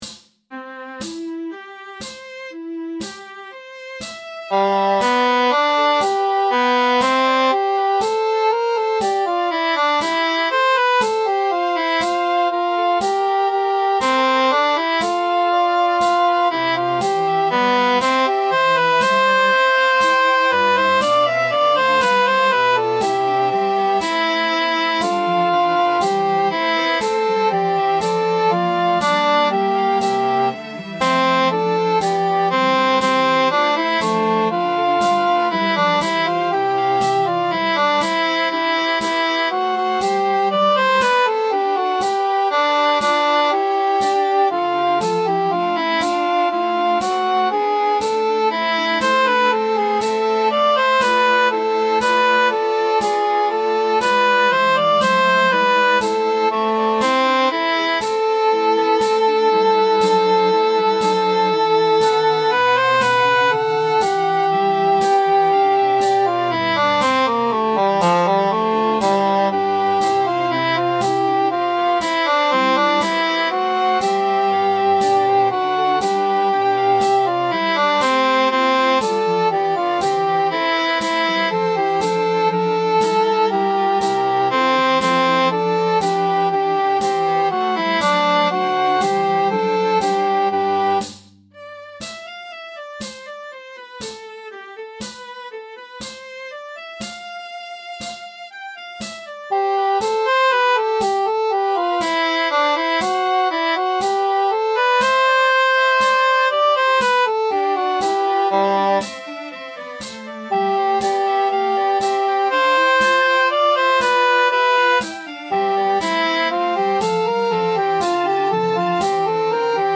BWV 230 auf zwei Halbe gebügelt mit Tamburin 2022 Alt als Mp3